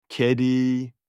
تلفظ آن چیزی شبیه به “کِدی” است، با تاکید روی حرف “ک”.